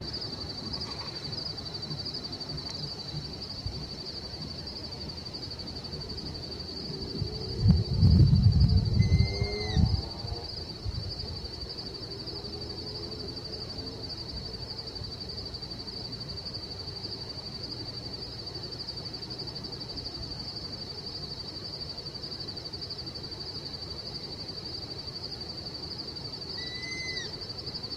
Lechuzón Orejudo (Asio clamator)
Se encontraba en zona de selva alrededor de uno de los arroyitos que cruza el parque.
Condición: Silvestre
Certeza: Vocalización Grabada
Lechuzon-Orejudo.mp3